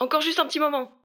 VO_ALL_Interjection_04.ogg